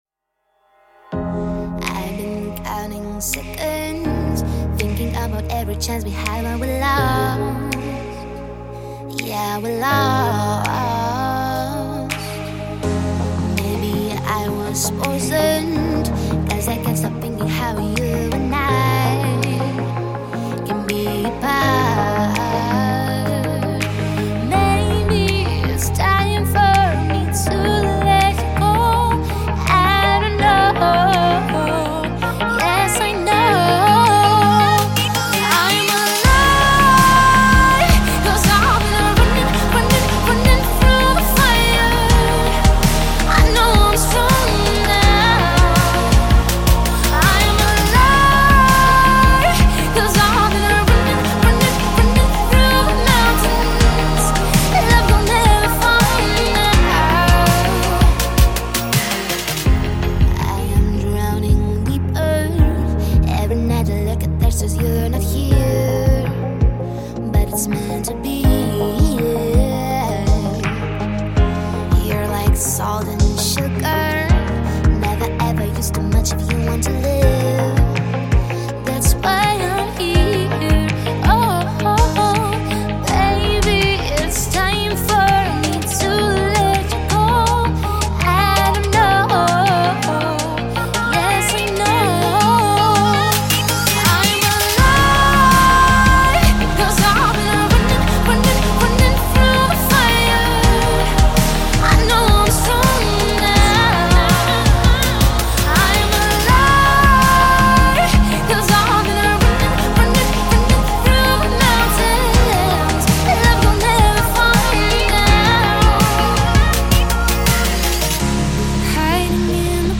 # Pop
# vocal # epic